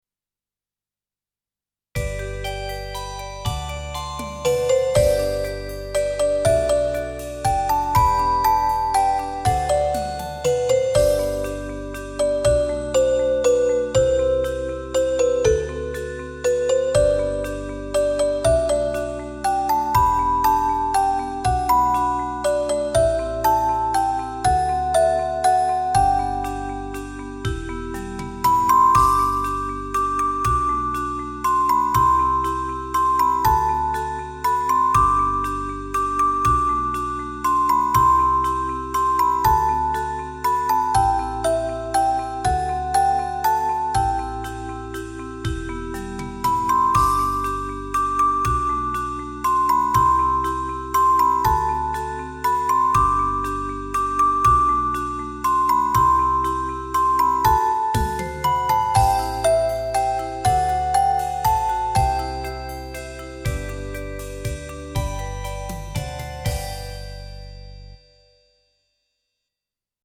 instrumental
4分の3拍子でメロディ・ボックス。
春先の感じが巧く出せたのではないかと一人悦に入ってます。